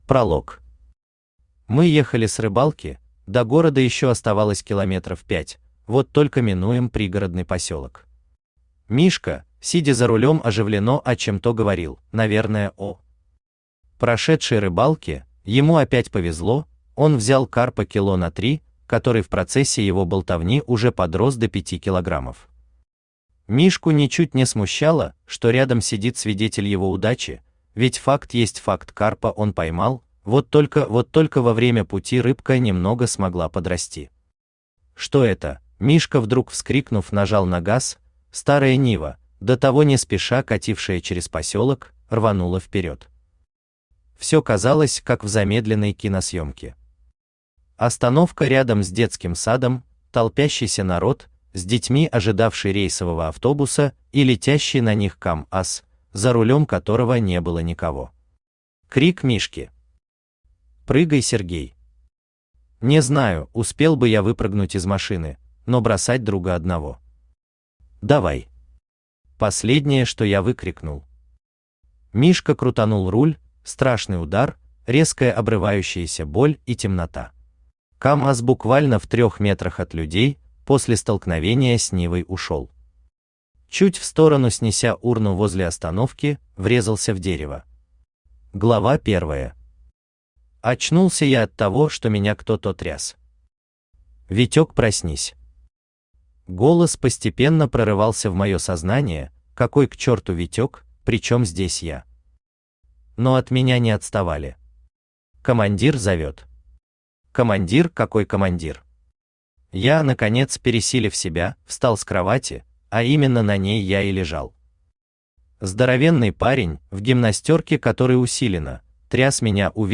Aудиокнига Подумаешь, попал Автор Валентин Георгиевич Вишняков Читает аудиокнигу Авточтец ЛитРес.